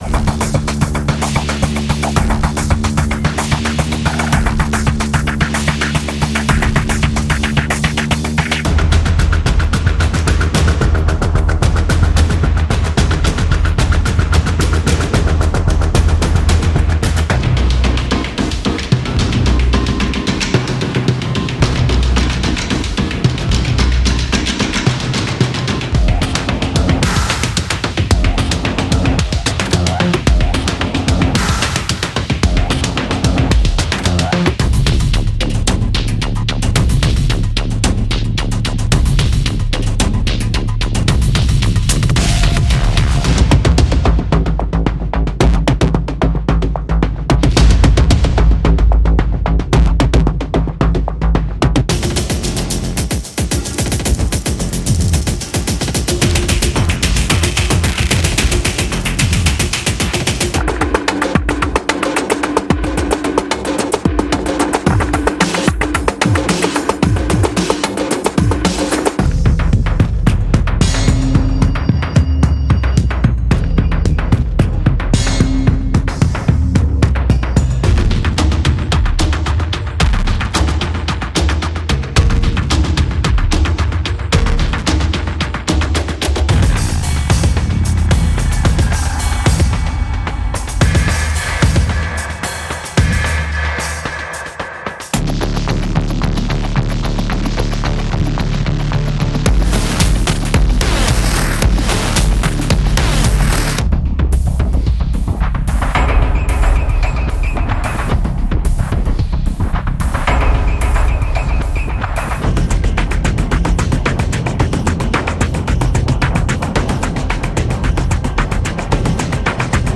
所有循环均以111 BPM（每分钟节拍数）记录。用具有震撼力的影音，晃动和混合上升音色的电影单发文件夹为您的配乐增添色彩。
• 电影预告片和电影乐谱循环和音效库
• 51个节奏循环– 111 BPM